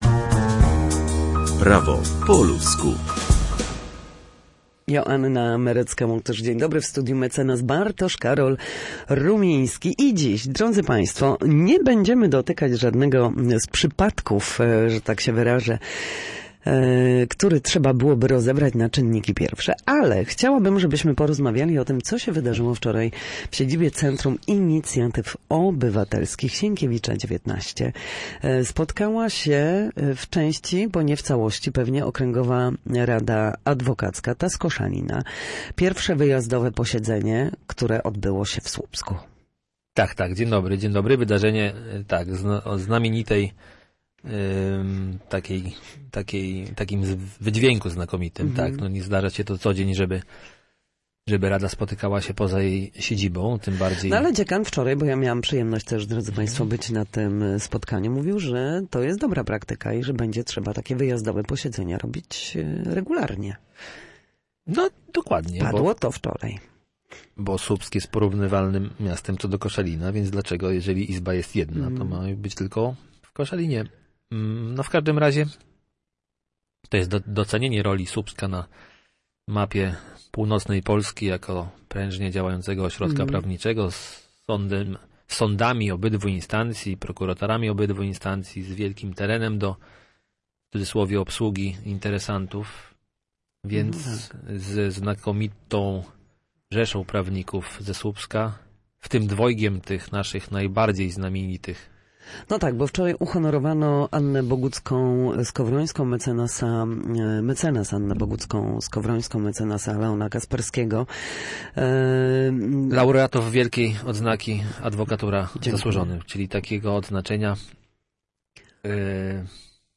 W każdy wtorek o godzinie 13:40 na antenie Studia Słupsk przybliżamy meandry prawa. W naszym cyklu prawnym zapraszamy ekspertów, którzy odpowiadają na jedno konkretne pytanie związane z funkcjonowaniem sądu lub podstawowymi zagadnieniami prawnymi.